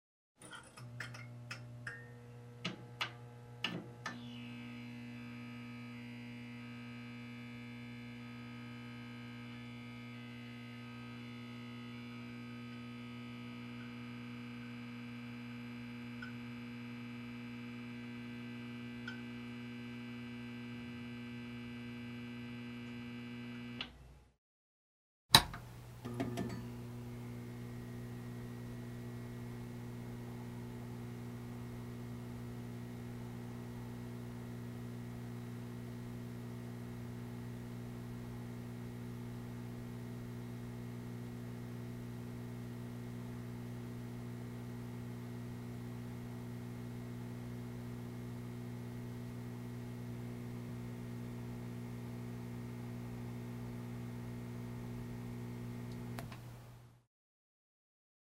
На этой странице собраны различные звуки, связанные с электрическим током: от мягкого гудения трансформаторов до резких искр и тресков короткого замыкания.
Звук включения и гудения лампы дневного света